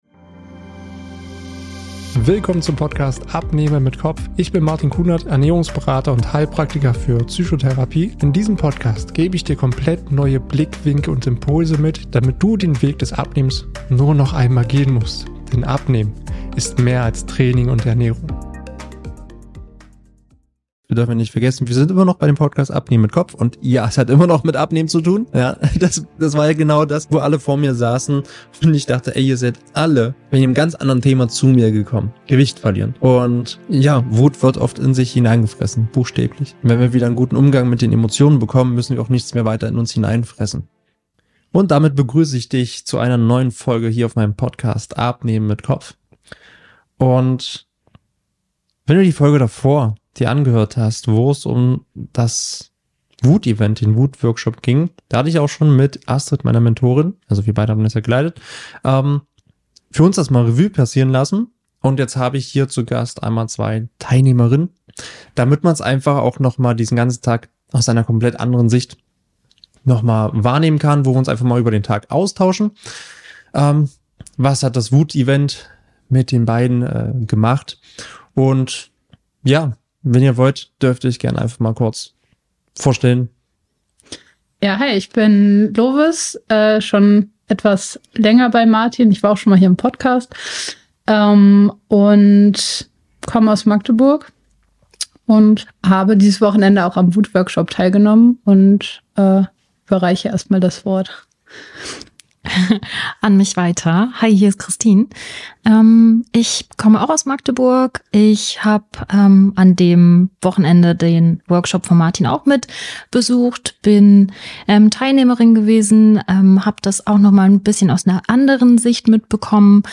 Heute habe ich zwei Teilnehmerinnen des Wut-Workshops zu Gast.